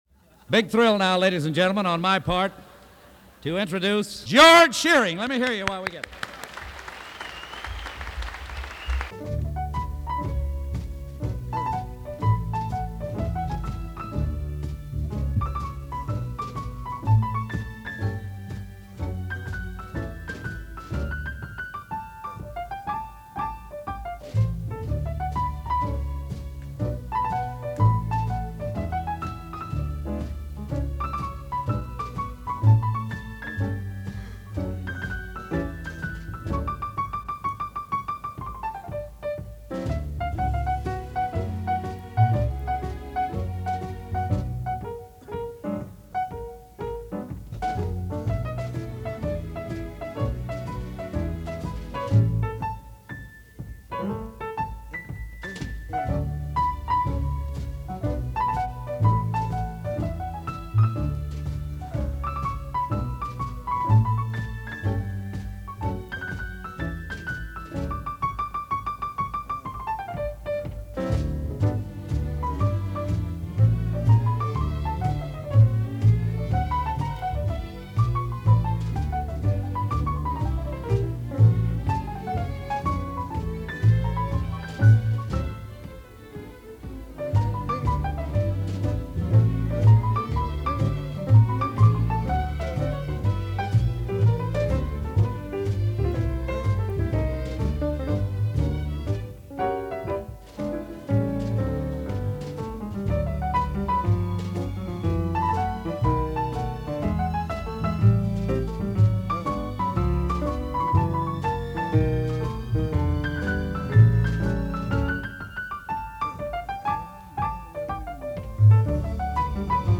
concert in benefit for The Boys Club of America